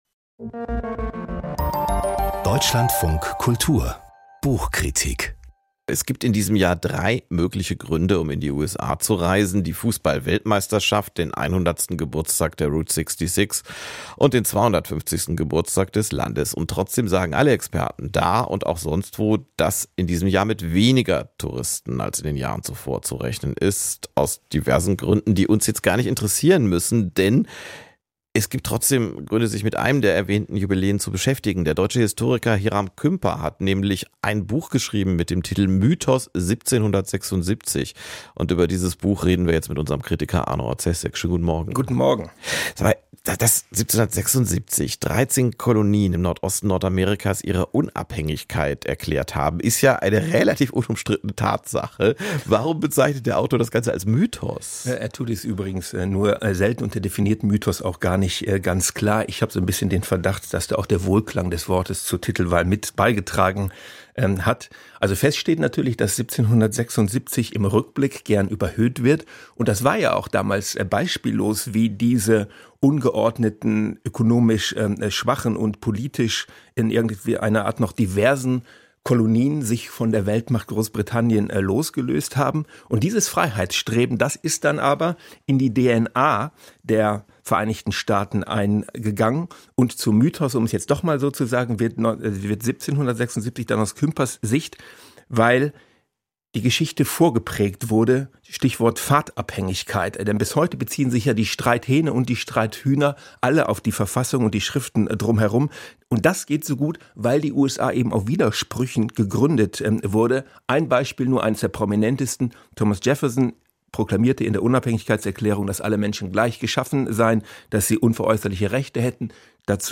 Buchkritik - Hiram Kümper: "Mythos 1776"